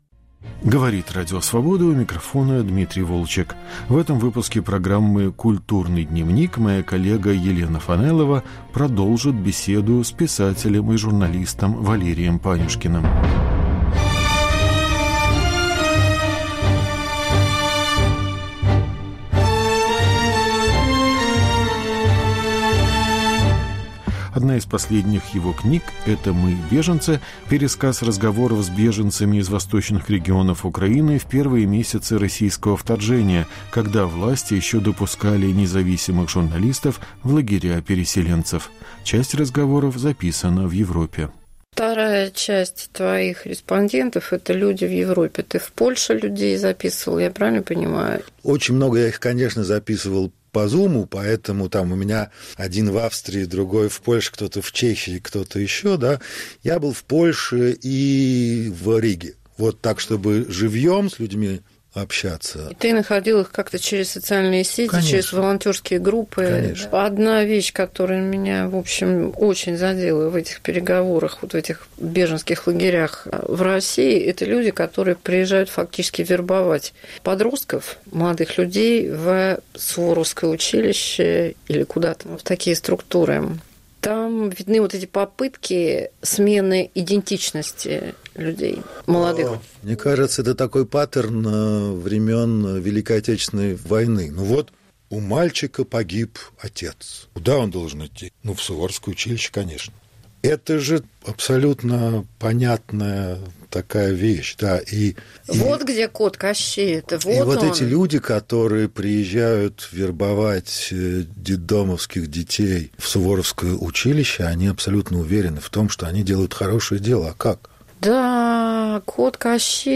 Автор книги о беженцах отвечает на вопросы Елены Фанайловой